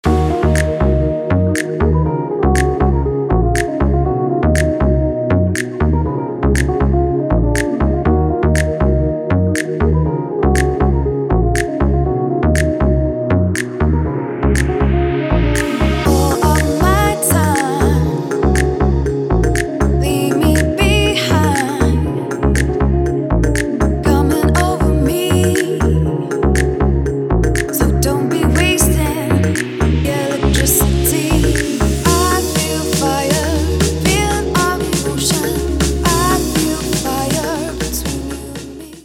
• Качество: 320, Stereo
гитара
ритмичные
женский вокал
восточные
Стиль: deep house